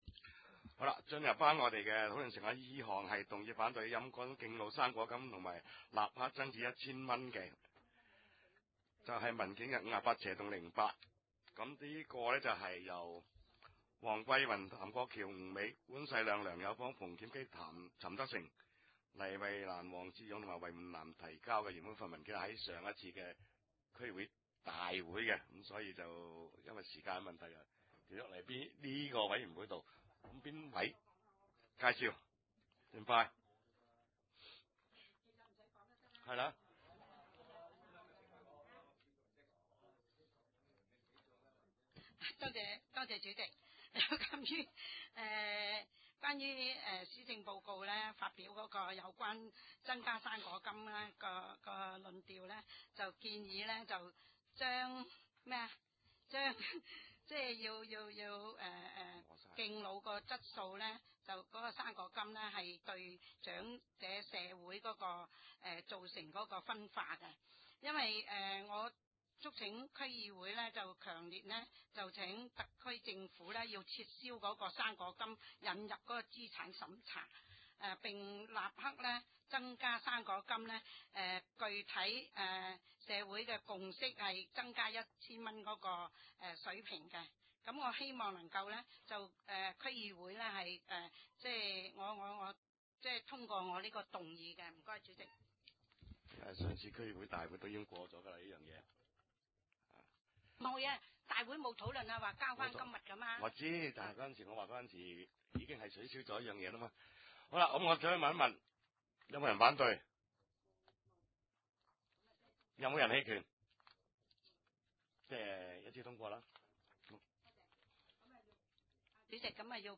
地點：深水埗區議會會議室